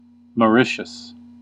Ääntäminen
Ääntäminen US UK : IPA : /məˈɹɪʃəs/ IPA : /mɔːˈɹɪʃəs/ Haettu sana löytyi näillä lähdekielillä: englanti Käännös Erisnimet 1. Mauritius Määritelmät Erisnimet Country in the Indian Ocean .